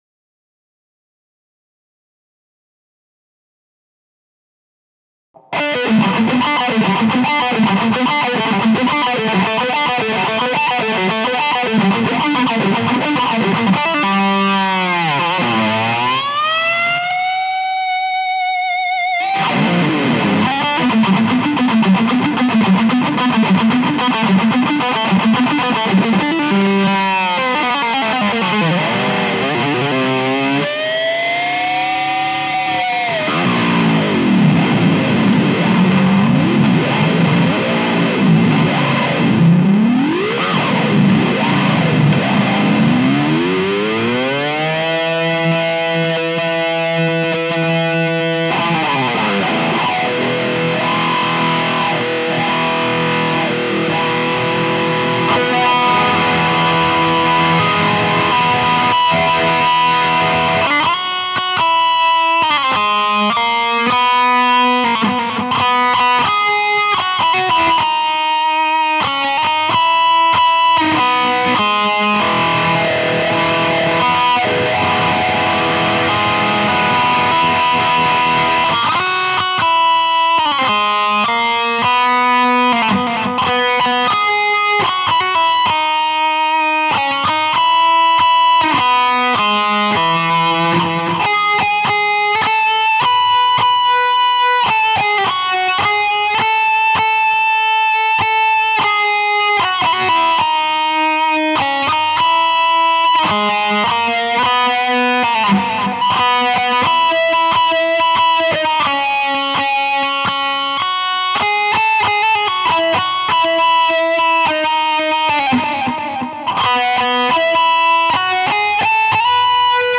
• Drums – Boss, Dr. Rhythm Drum Machine
• Recorded at the Park Springs Recording Studio